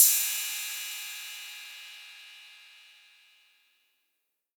808CY_3_Tape_ST.wav